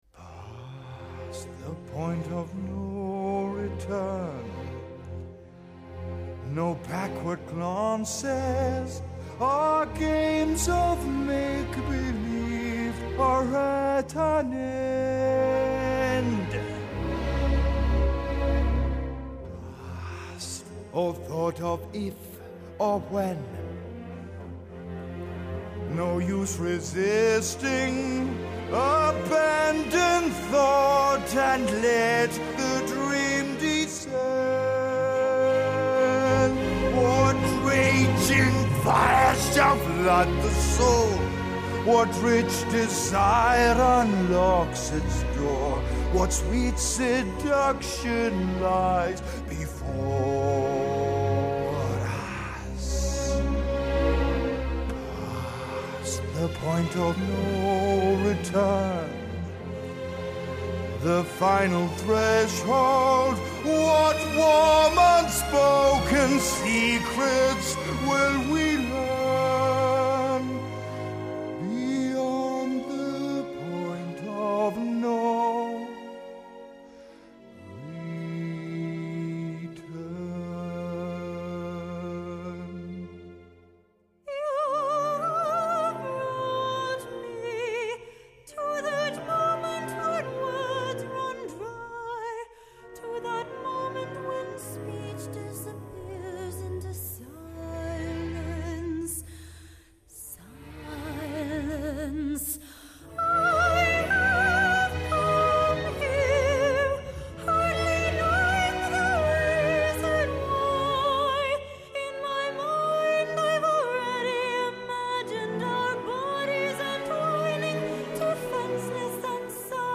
音乐类型：电影配乐